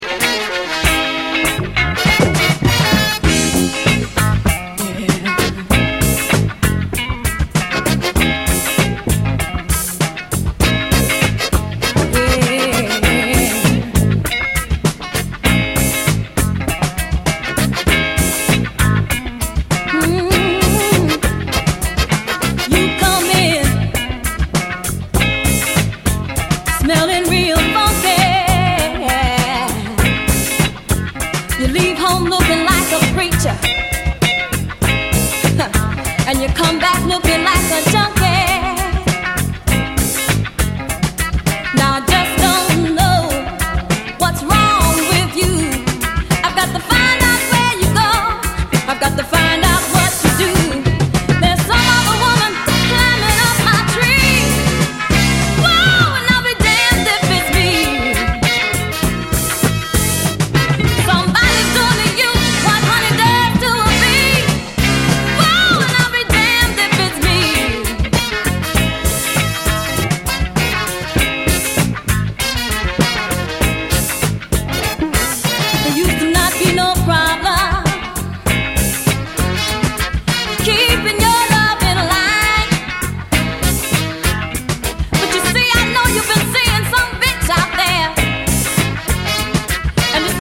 】1977年リリースのオリジナルは4,5万は下らない激レア・ファンキー・ソウル〜ディスコ・アルバムが正規復刻！